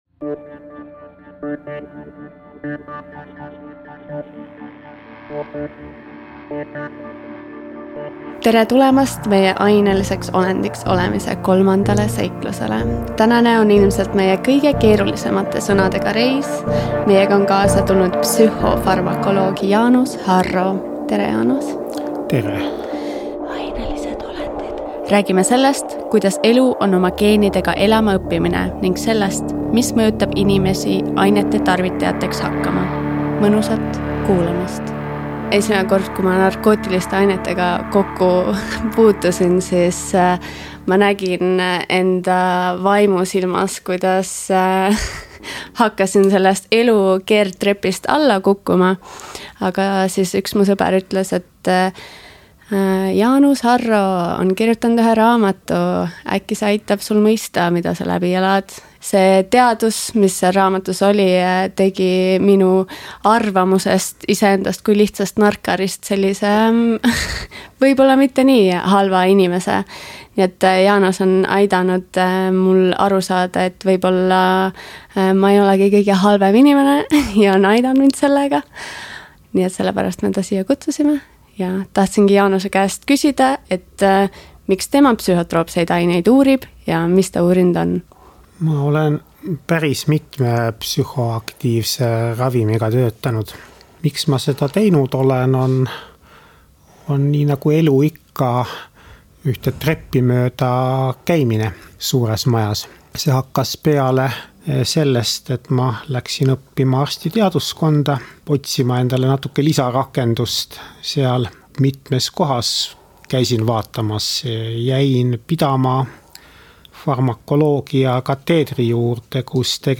Vestlus narkootikumide tarvitaja ja teadlase vahel.